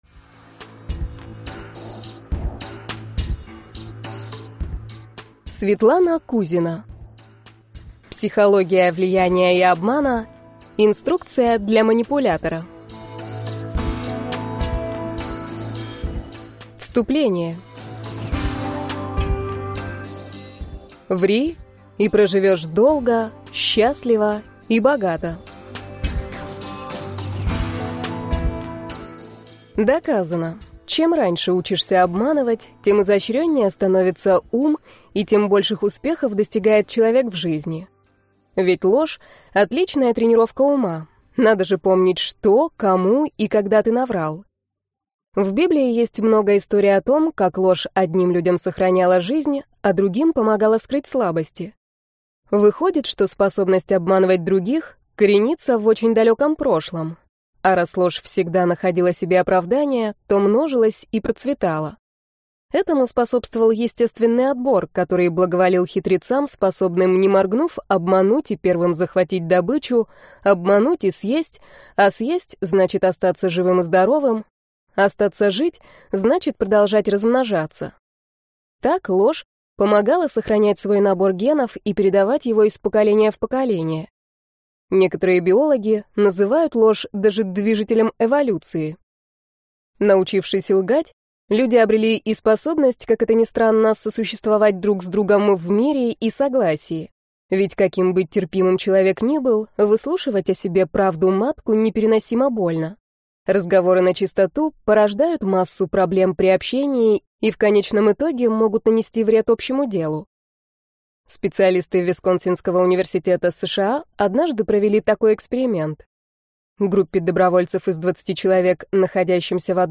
Аудиокнига Психология влияния и обмана. Инструкция для манипулятора | Библиотека аудиокниг